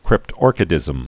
(krĭp-tôrkĭ-dĭzəm) also crypt·or·chism (-kĭzəm)